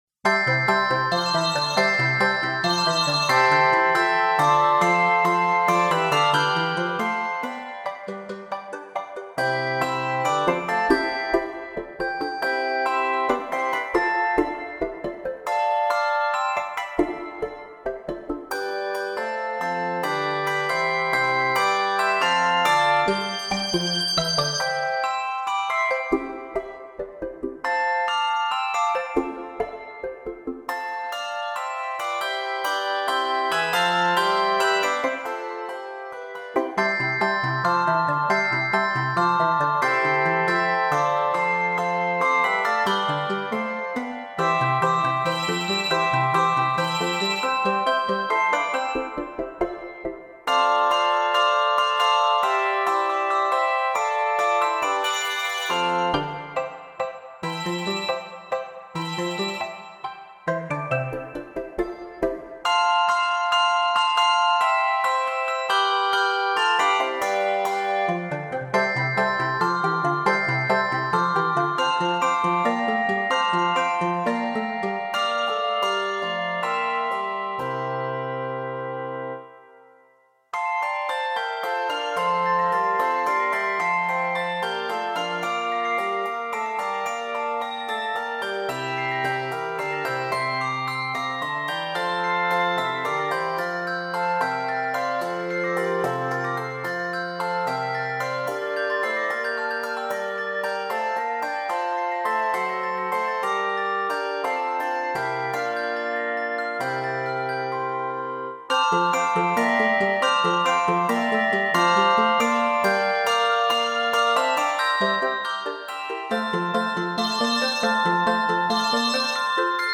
Set in C Major and F Major, this arrangement is 88 measures.